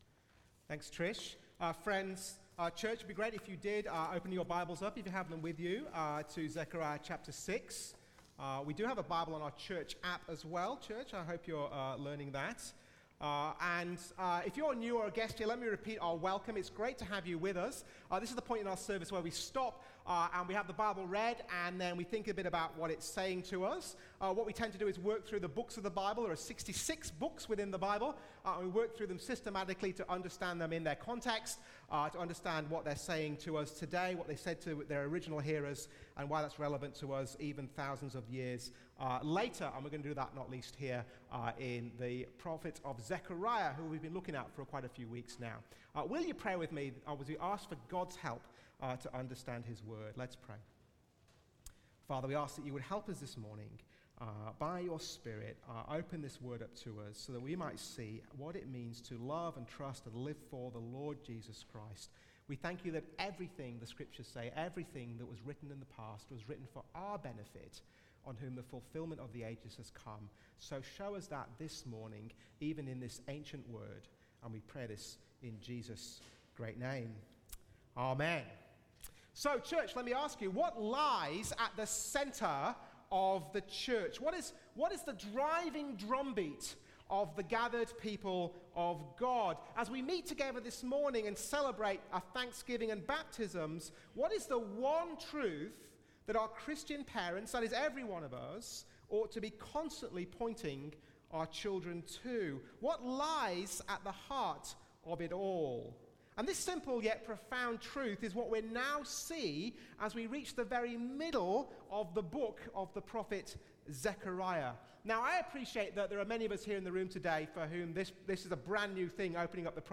Zechariah 6:9-15 Sunday sermon